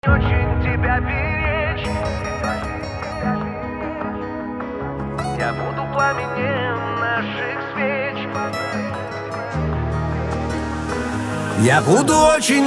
Господа где найти такие Loop для шансона
На заднем плане loop drum